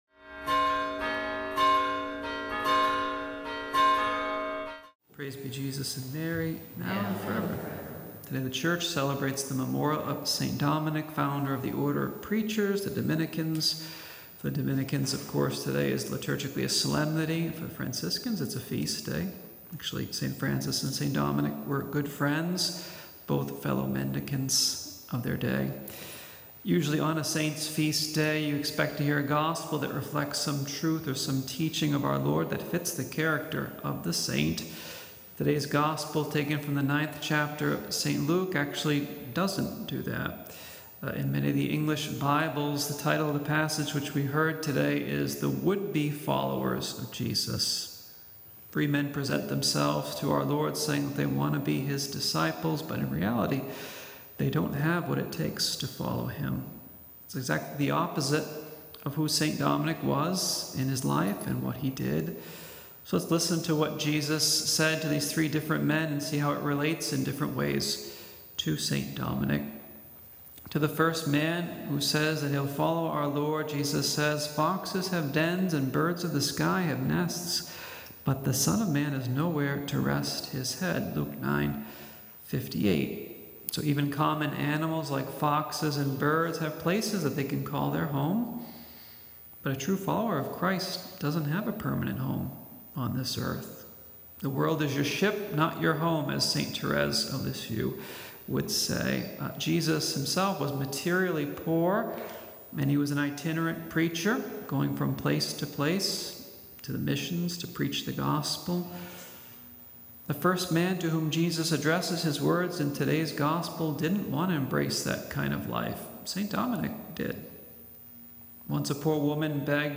St. Dominic’s Life of Apostolic Zeal- Aug 08 – Homily